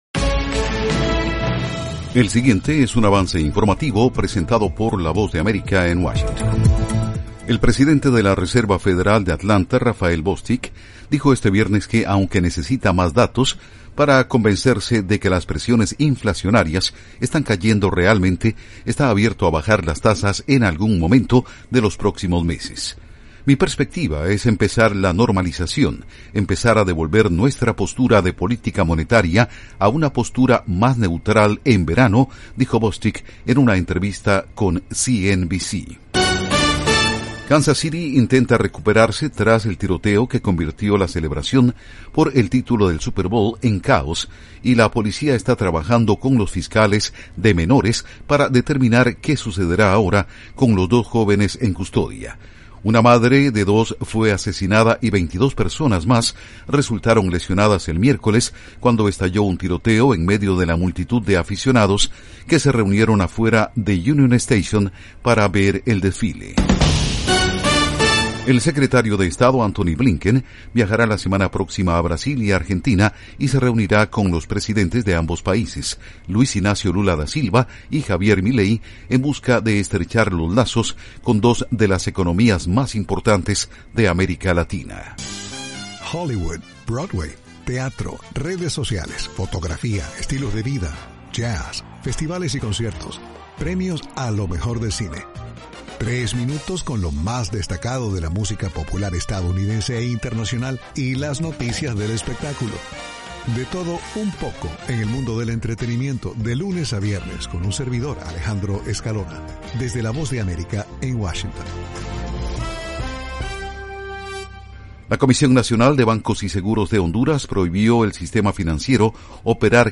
Avance Informativo 2:00 PM